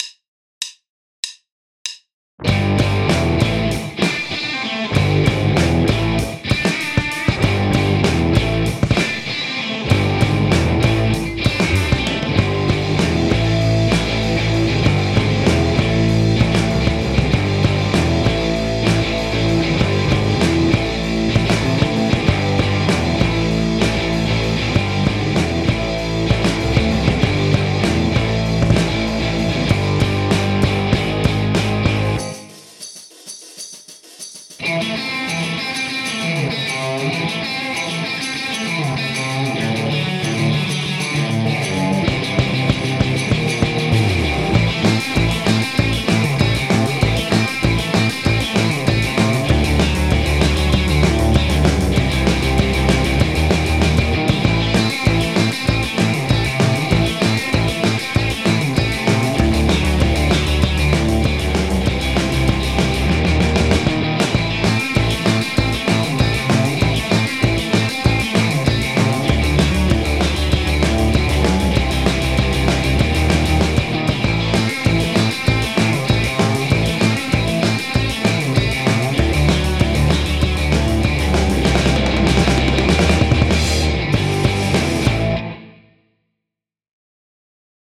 Voici un backing track qui contient quelques riffs
Le playback :